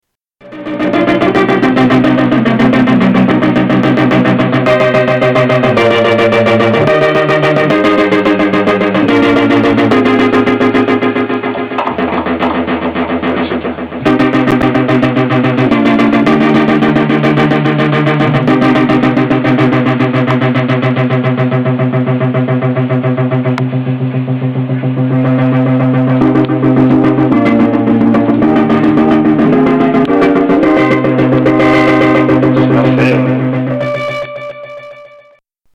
Tags: Moog MemoryMoog Sounds Moog MemoryMoog MemoryMoog synth sounds Synthesizer